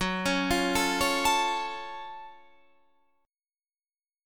F#mM7 chord